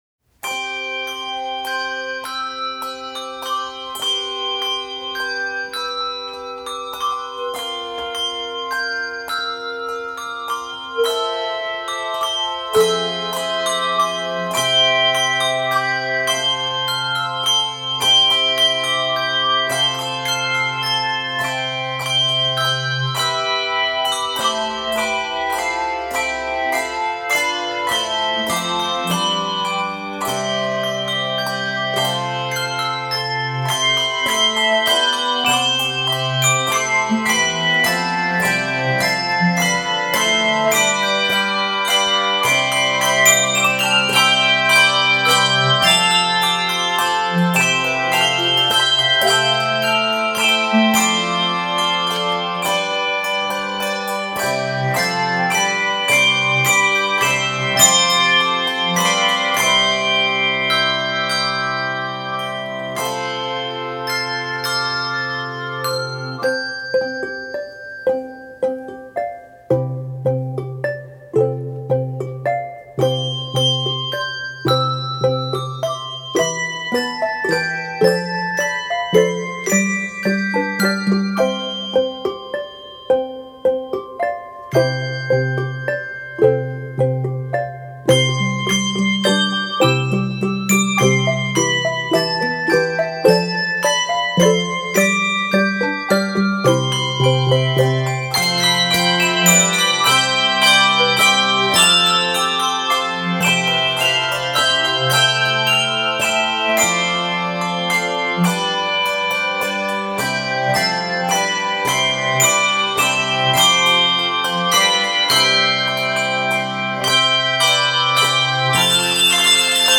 Composer: Traditional Hymntune
Voicing: 2 or 3 oct